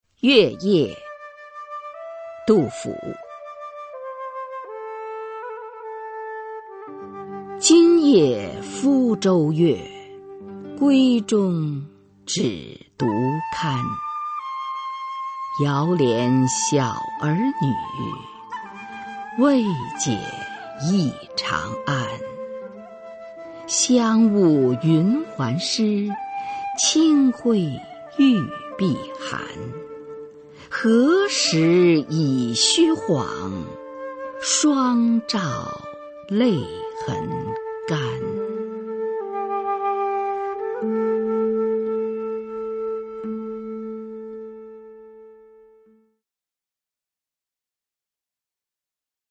[隋唐诗词诵读]杜甫-月夜（女） 古诗文诵读